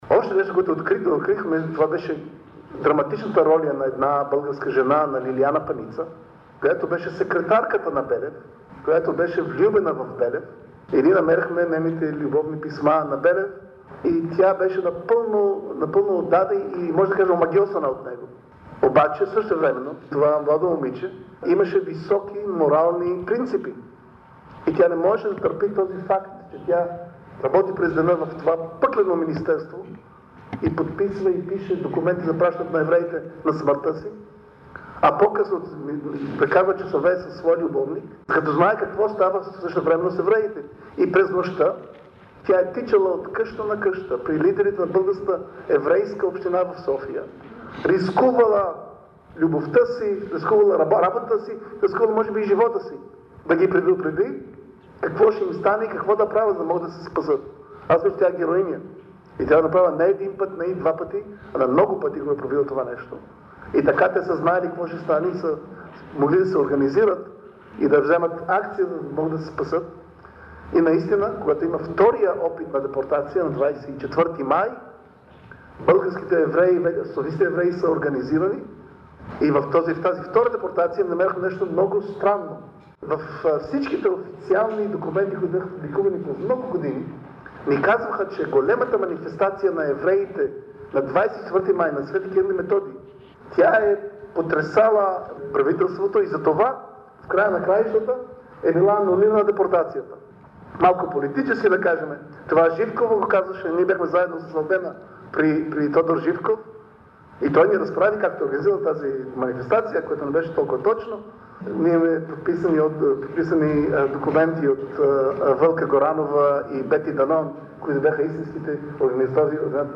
На пресконференцията при представянето на документалната лента в България през 2001 година проф. Михаел Бар-Зоар подчертава ключовото значение на разкритите документални свидетелства за спасяването на българските евреи.